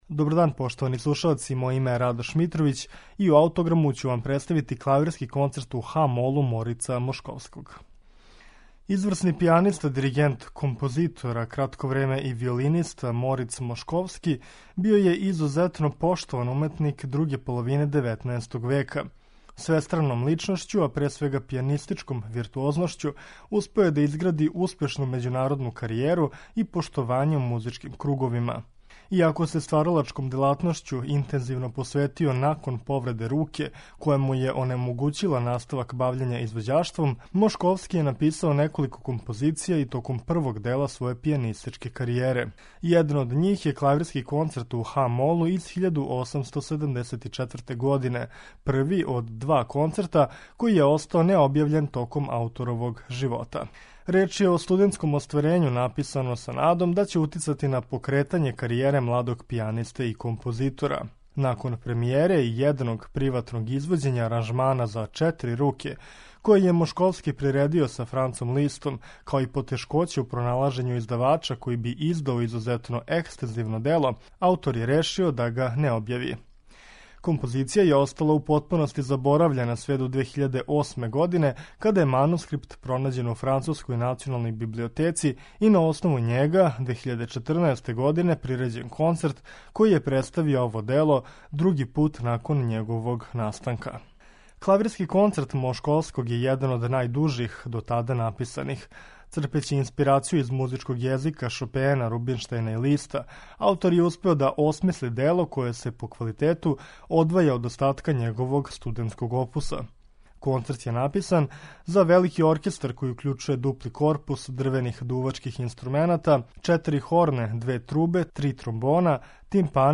Клавирски концерт Мошковског у ха-молу